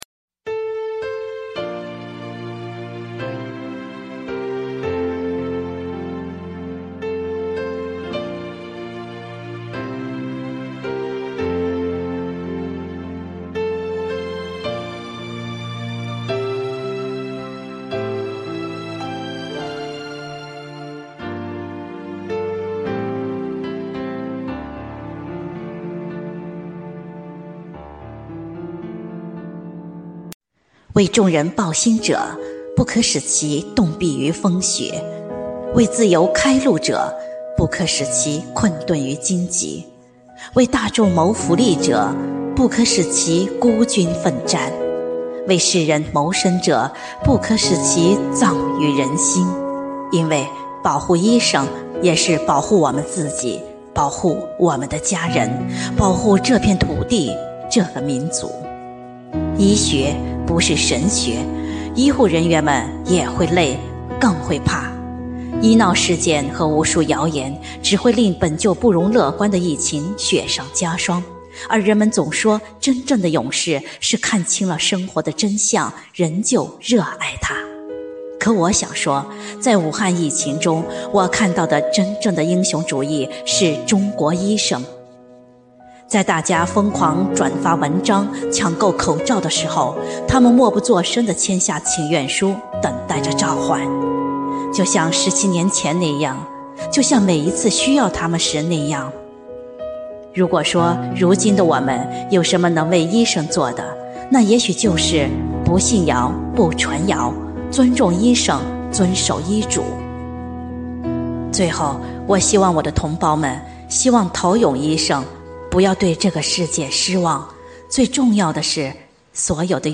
朗 诵 作 品